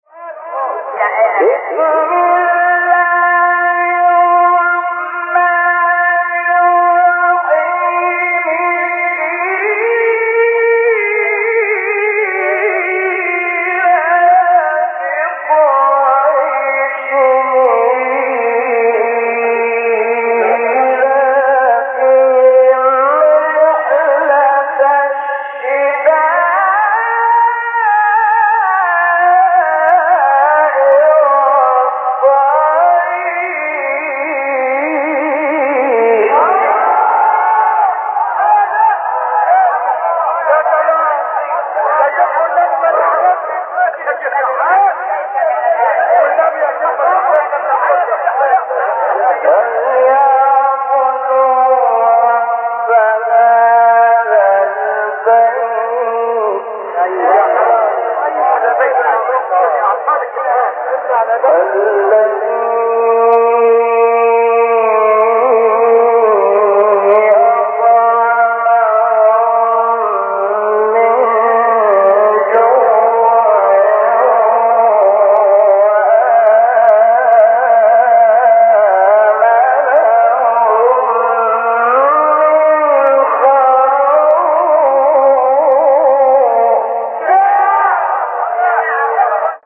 تلاوت زیبای سورۀ قریش توسط استاد استاد شحات | نغمات قرآن | دانلود تلاوت قرآن